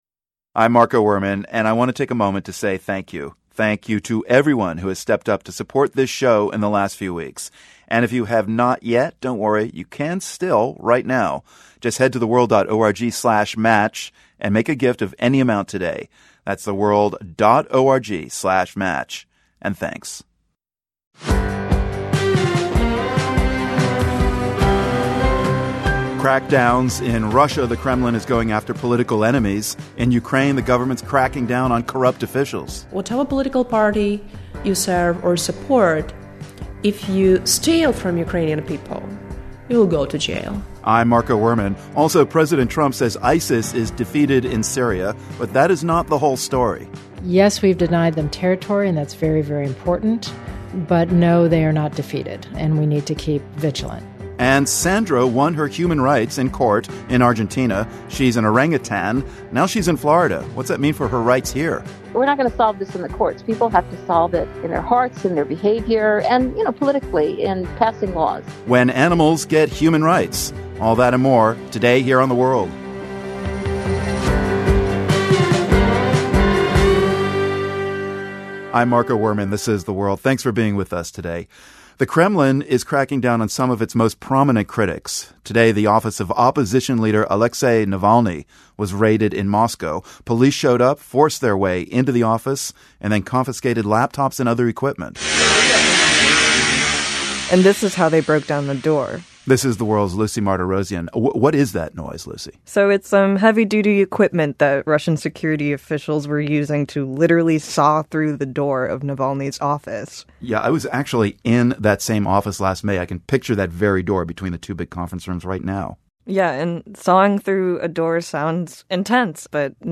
The World is a public media news program that relies on the support of listeners like you.